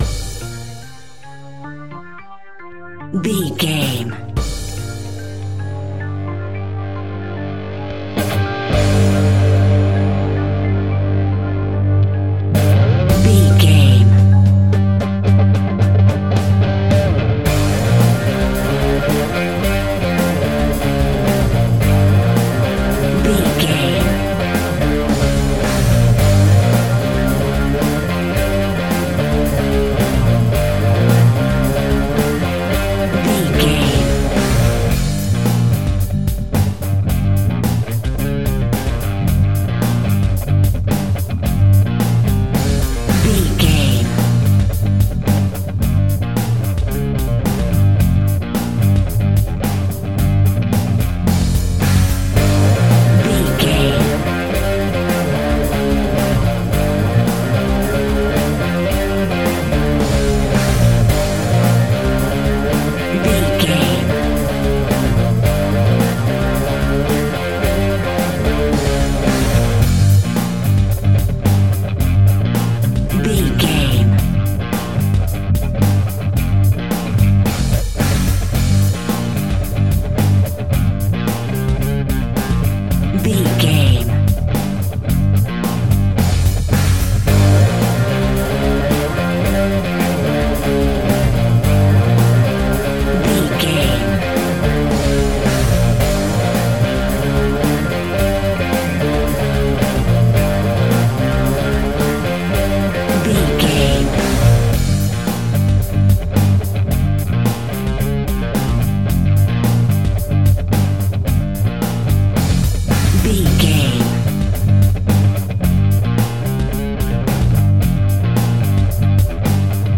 Heavy Rock Metal.
Epic / Action
Aeolian/Minor
heavy metal
blues rock
distortion
hard rock
Instrumental rock
drums
bass guitar
electric guitar
piano
hammond organ